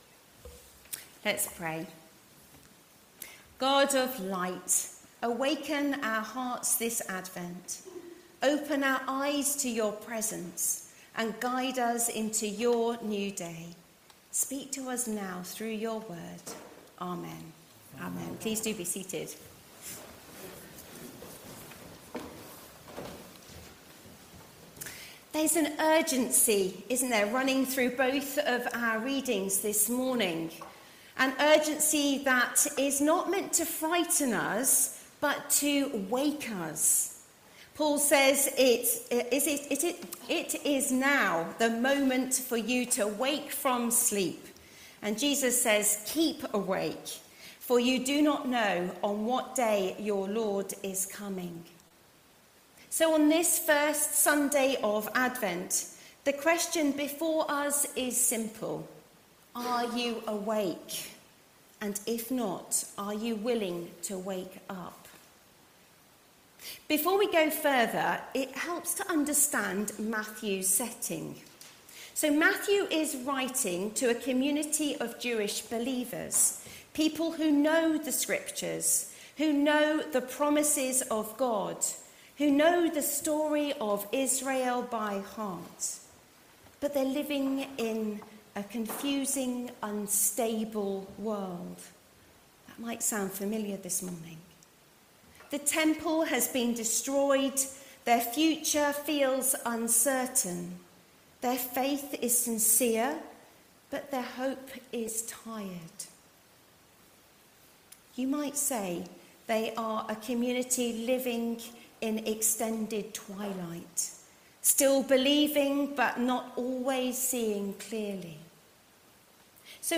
– St. Bartholomew's Church